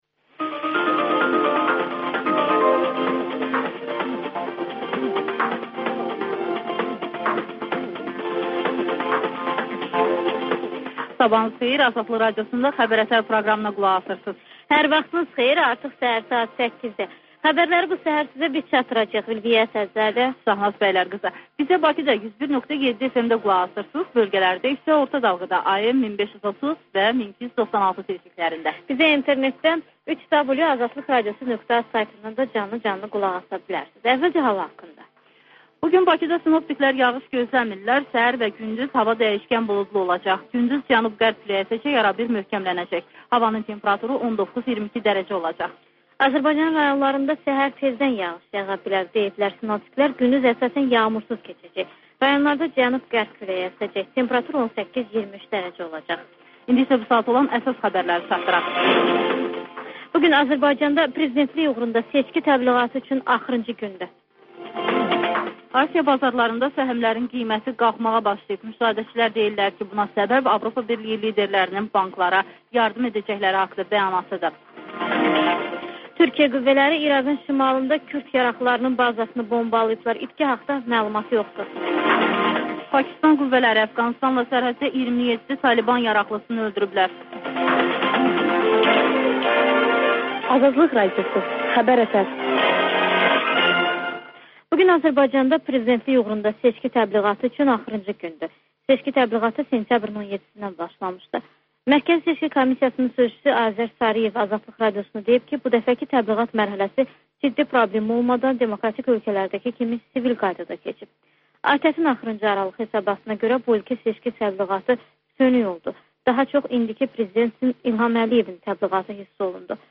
Xəbər-ətər: xəbərlər, müsahibələr və İZ: mədəniyyət proqramı